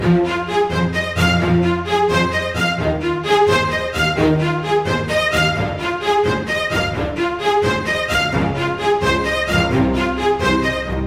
噔噔噔的弦乐
描述：漂亮的舞蹈流行音乐弦乐
标签： 130 bpm Pop Loops Strings Loops 2.48 MB wav Key : Unknown
声道立体声